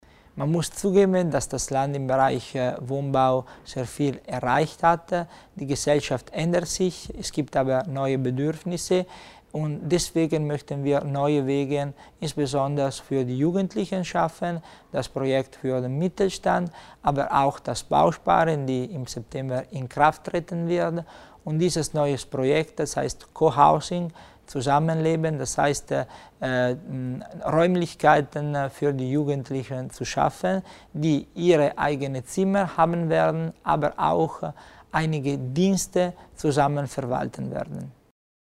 Landesrat Tommasini über Projekte im Wohnungsbau
Eigenständiges Wohnen sei der Schlüssel zur Eigenständigkeit junger Menschen und damit zur Entfaltung von deren Potential, betonte Tommasini heute in seinem Mediengespräch zum Legislaturende an der italienischen Gewerbeoberschule in Bozen.